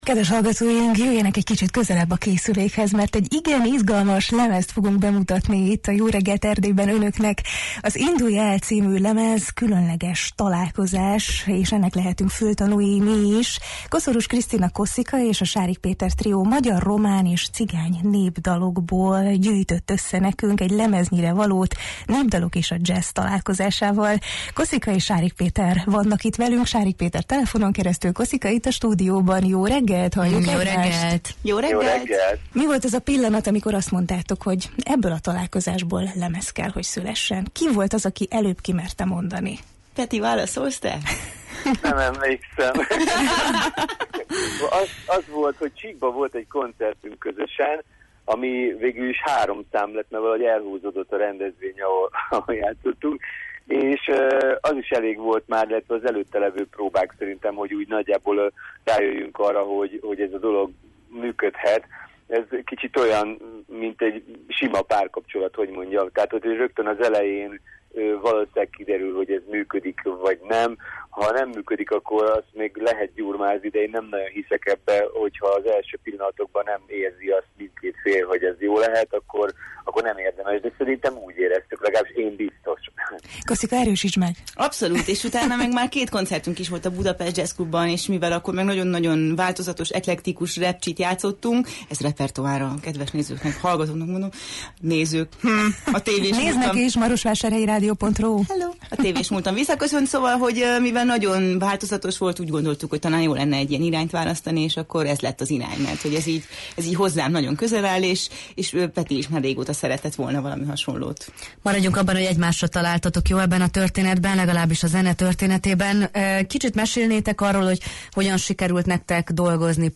A friss albumról, az alkotás öröméről és a közös hang megtalálásáról beszélgettünk